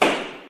tradeAccepted.ogg